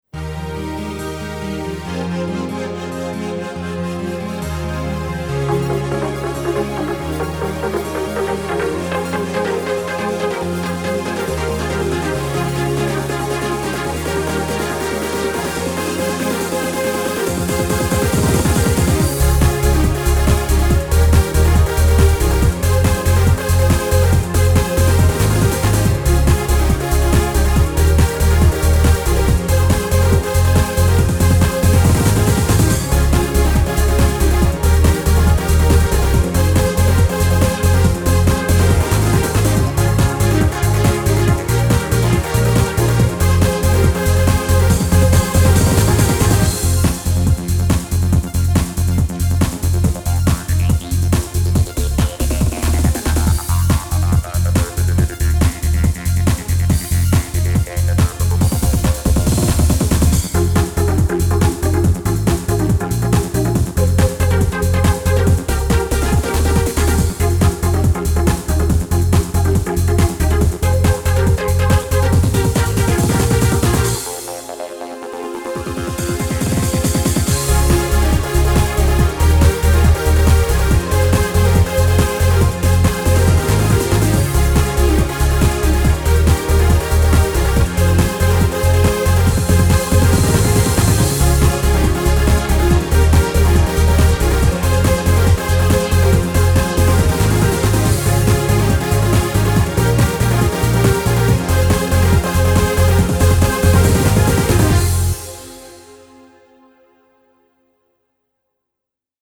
style/genre: energetic: trance, dance, house
music usage: frequent, mainly background